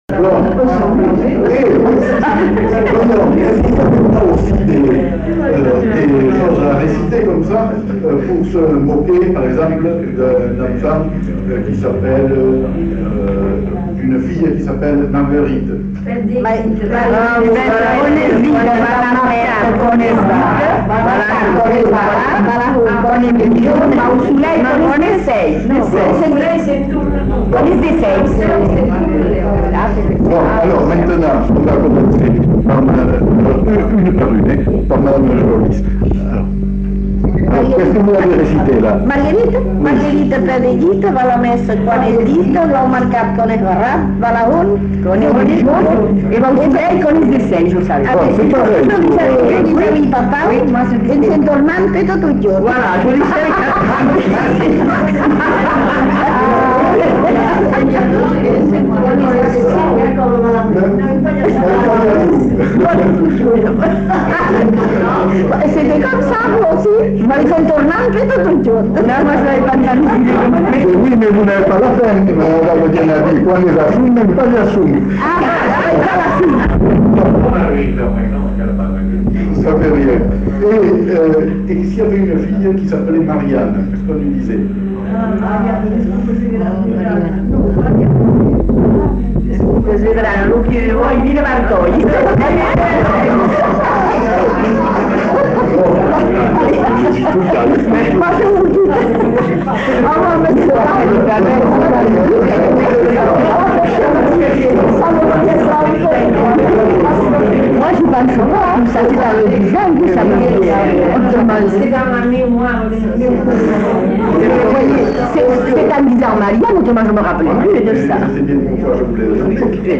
Aire culturelle : Bazadais
Genre : forme brève
Type de voix : voix de femme
Production du son : récité
Notes consultables : Suivi d'une autre formulette sur le prénom Marianne mais très difficile à entendre.